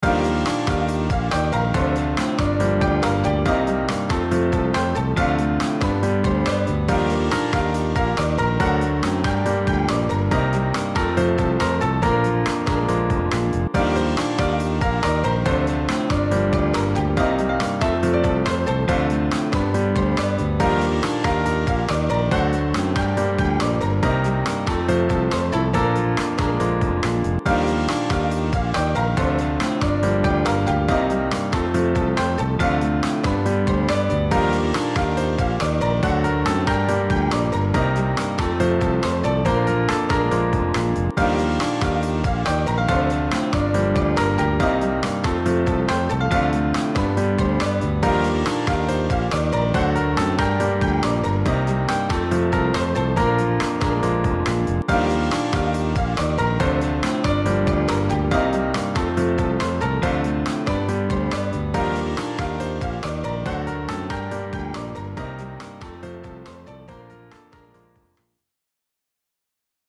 BGM
インストゥルメンタルエレクトロニカショート